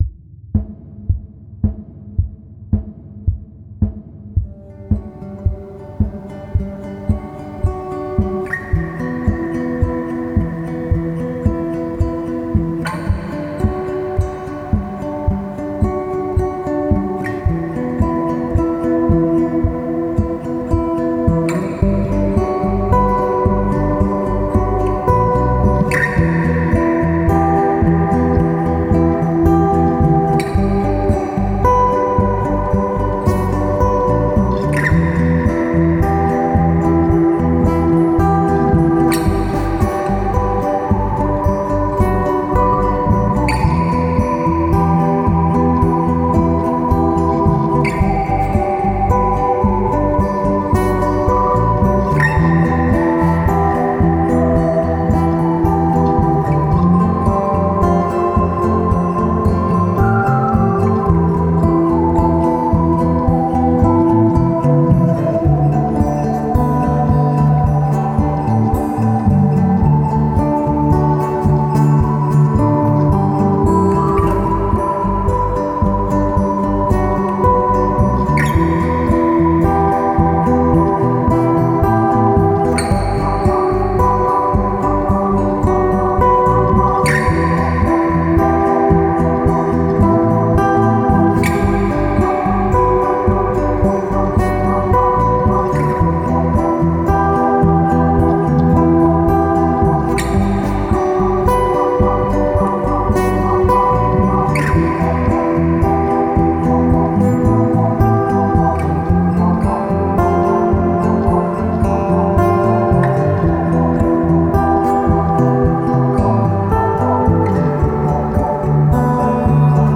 Home > Music > Blues > Smooth > Folk > Medium